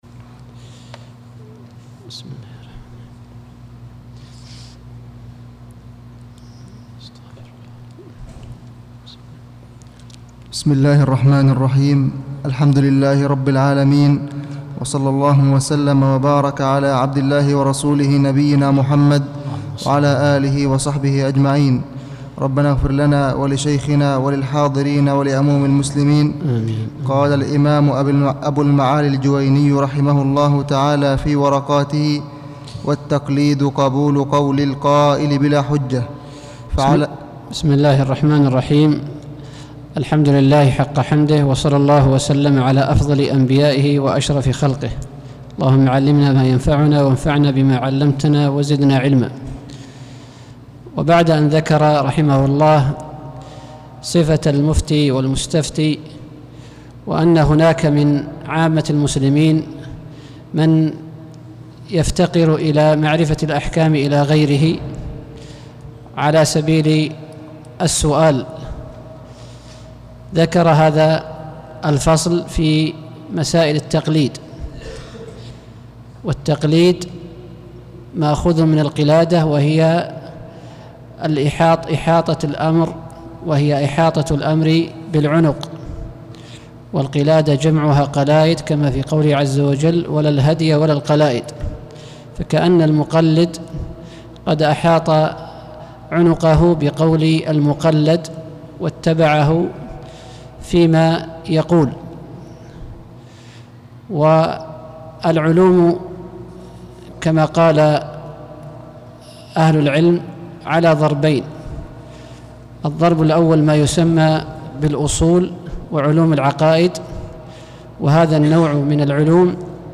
الدرس الثاني والعشرون: التقليد – الاجتهاد